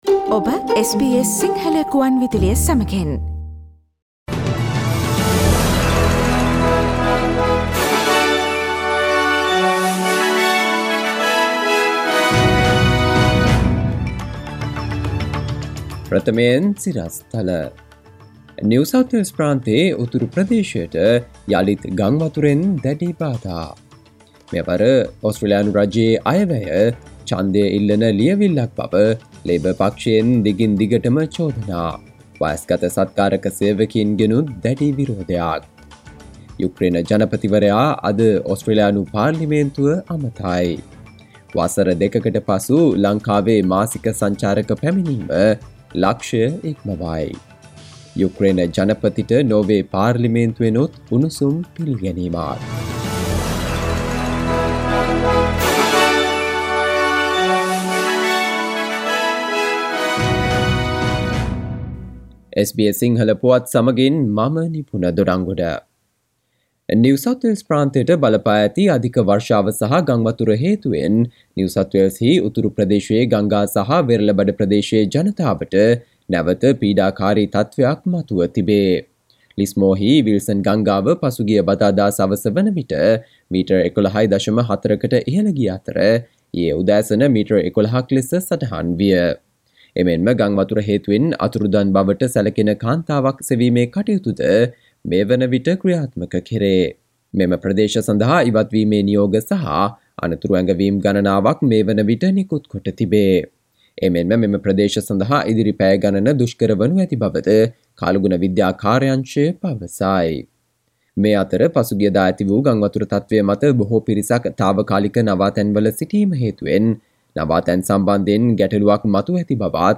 සවන්දෙන්න 2022 මාර්තු 31 වන බ්‍රහස්පතින්දා SBS සිංහල ගුවන්විදුලියේ ප්‍රවෘත්ති ප්‍රකාශයට...